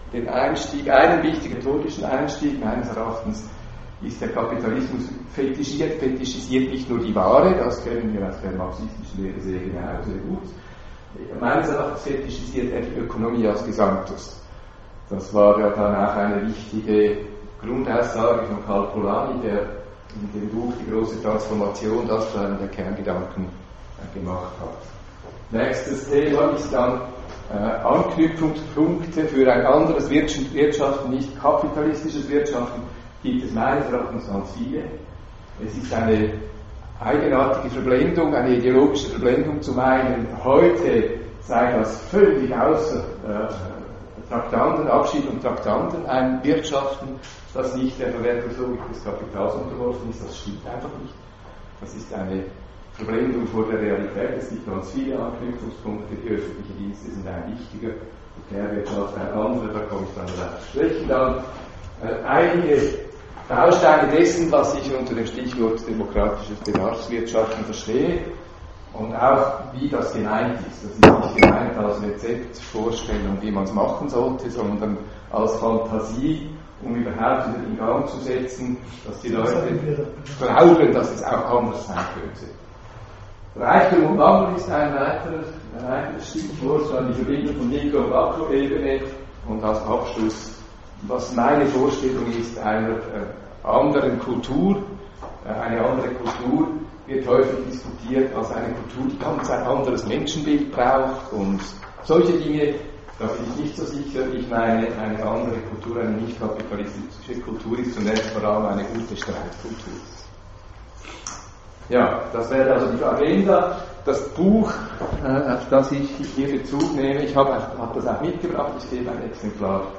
Diese Veranstaltung bot sehr viel Raum für Diskussion aber auch Zustimmung.